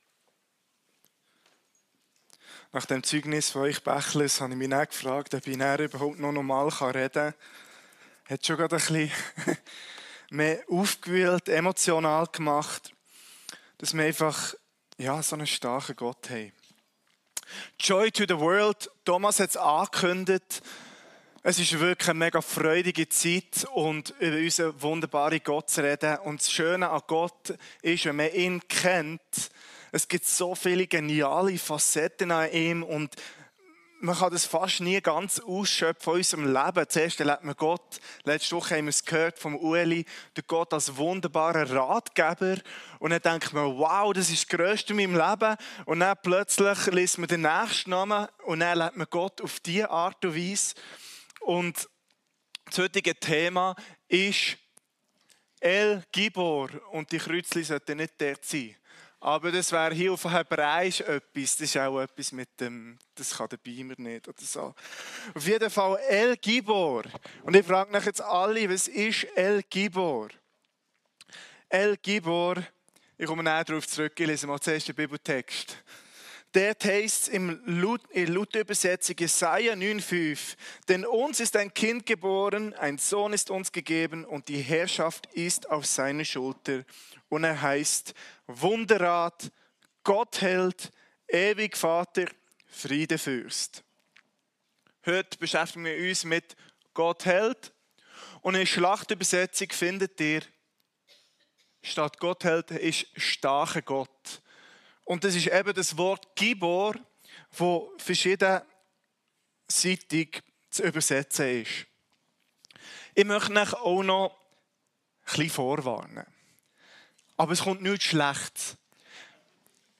Predigt Gewissheit im Leben